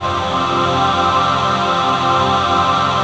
DM PAD4-02.wav